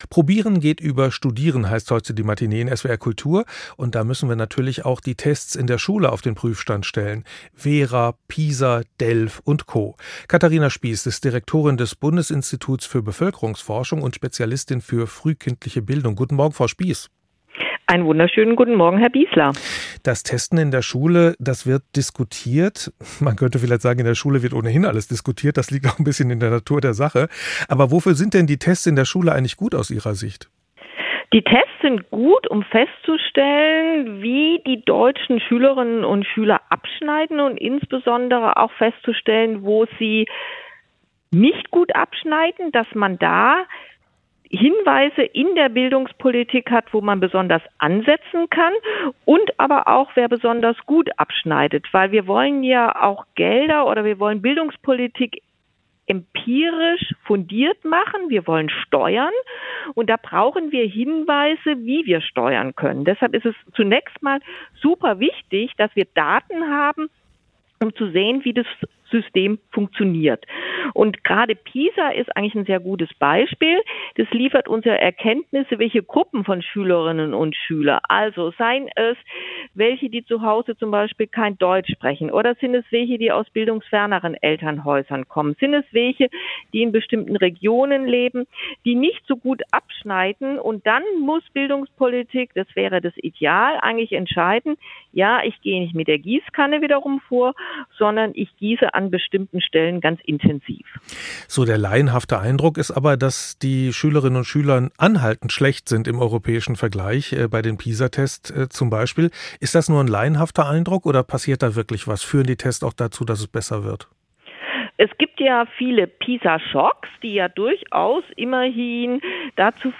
Ein spannendes Gespräch über Testeritis, Chancengleichheit und die Zukunft des Bildungssystems.